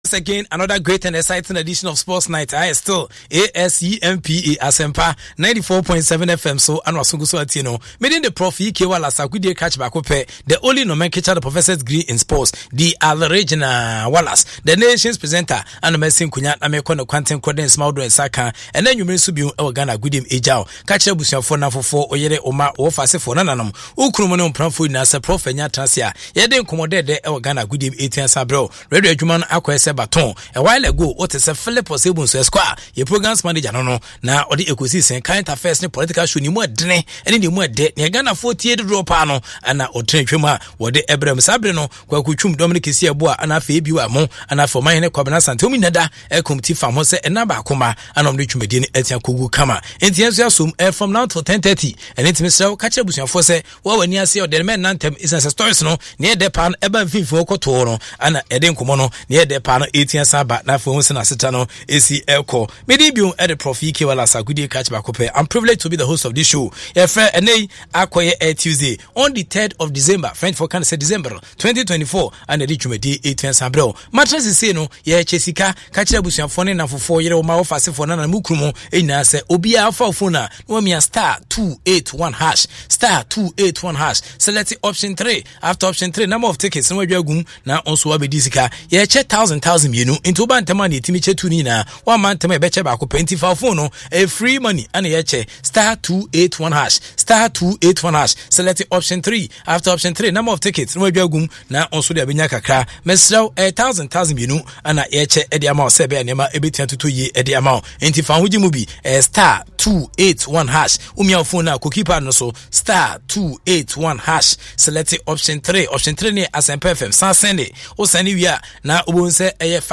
A humour-packed sports update show with foreign sports, news tit-bits and special interviews